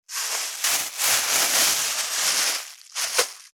2025年3月1日 / 最終更新日時 : 2025年3月1日 cross 効果音
607コンビニ袋,ゴミ袋,スーパーの袋,袋,買い出しの音,ゴミ出しの音,袋を運ぶ音,